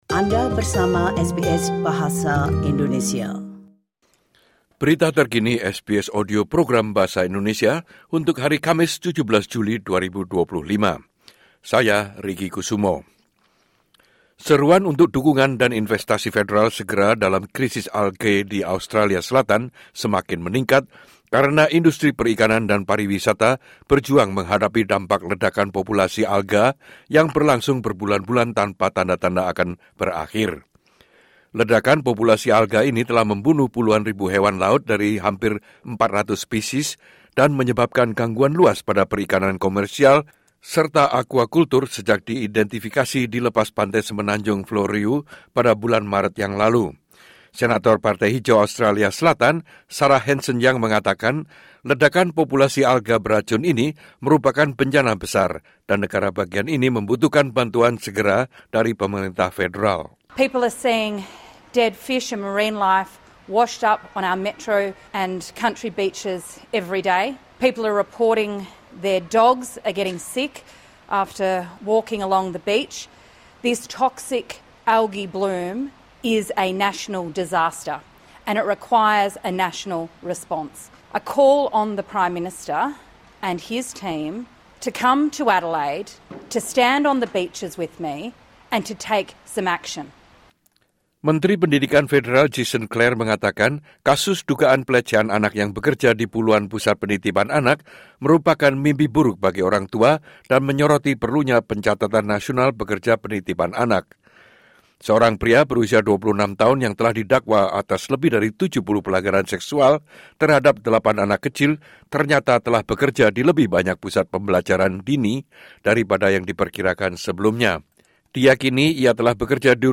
Berita terkini SBS Audio Program Bahasa Indonesia – Kamis 17 Jul 2025